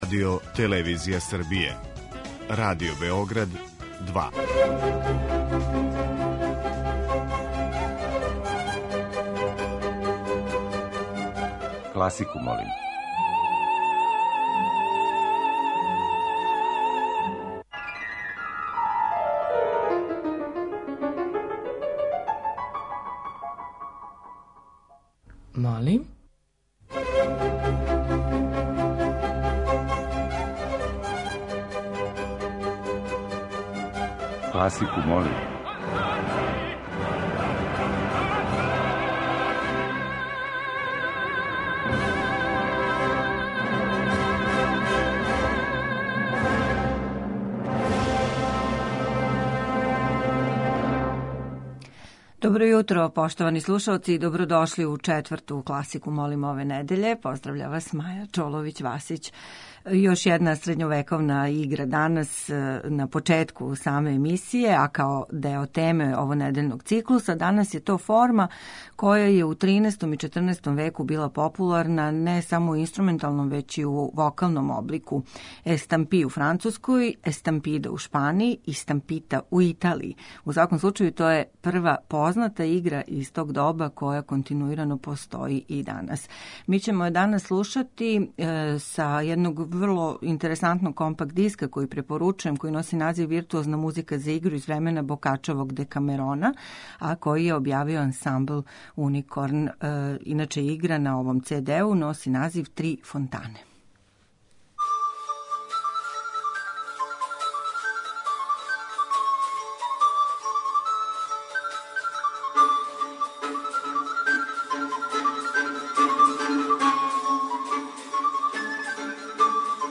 Средњевековне и ренесансне игре
Још један избор најразноврснијих музичких фрагмената за хит недеље биће обједињен средњовековним и ренесансним играма.